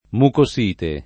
[ muko S& te ]